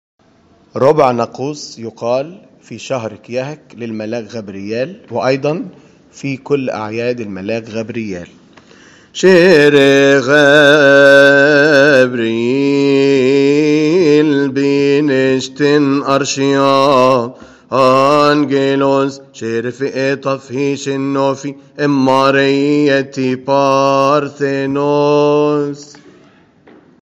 المرتل
قبطي
Archangel-Gabriel-Verses-of-the-Cymbals.mp3